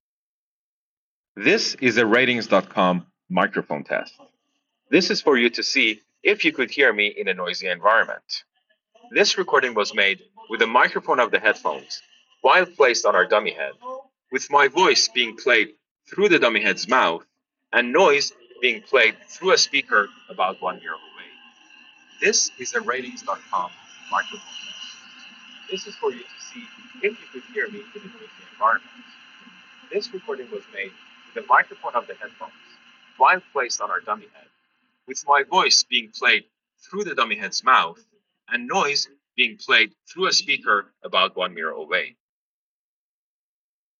loud environments.